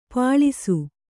♪ pāḷisu